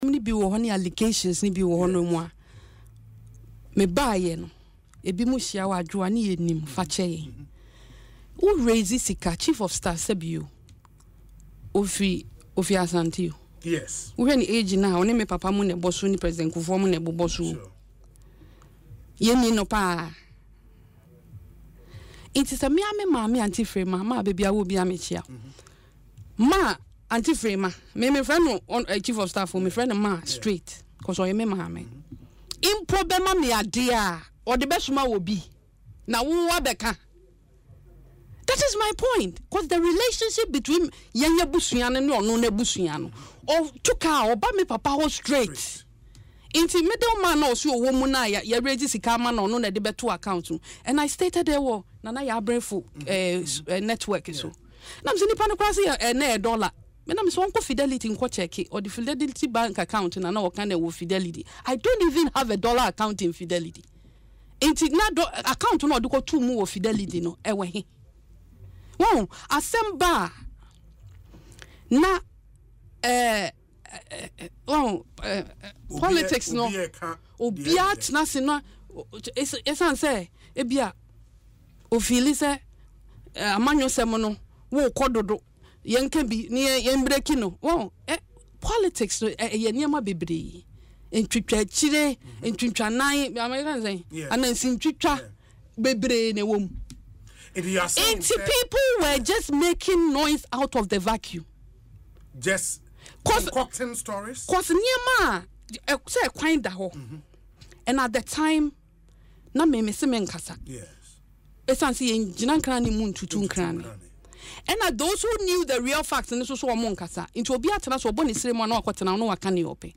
In an interview on Asempa FM’s Ekosii Sen, Adwoa Safo refuted the allegations, calling them mere attempts to tarnish her reputation.